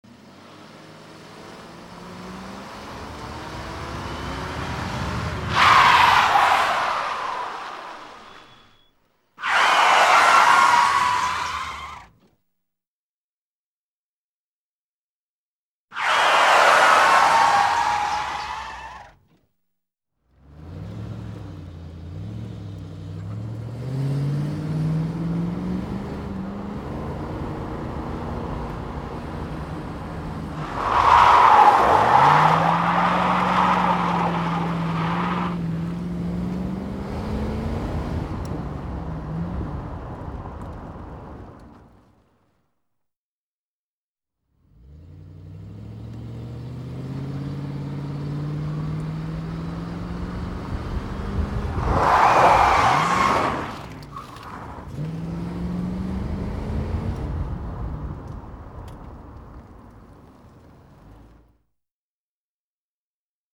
На этой странице собраны звуки тормозов машин в разных ситуациях: от резкого экстренного торможения до плавного замедления.
Шум тормозов автомобиля